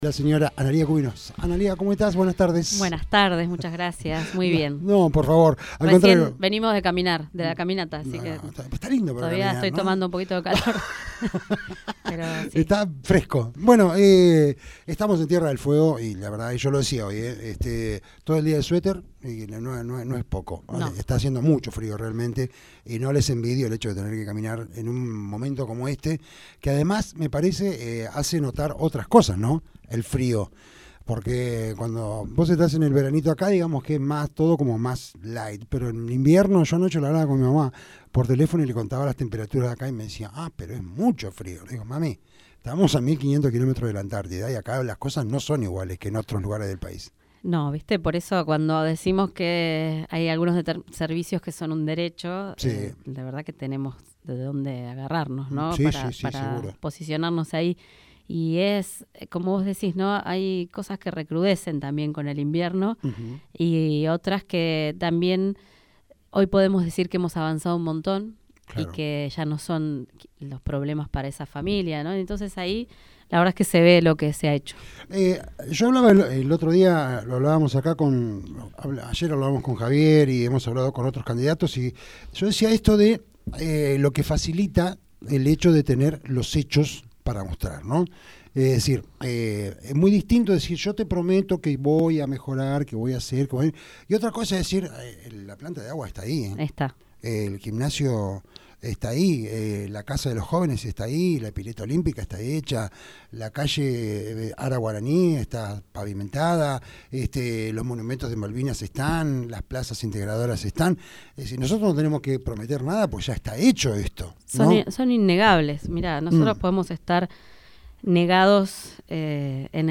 Mierc 29/05/19.- Analía Cubino, pasó por Fm nuestras voces y dejo una serie de adelantos y concreciones de la gestión a lal vez que explico, como se hará para continuar con la gestión en medio de la crisis, los doble discursos, los conversos y las chicanas.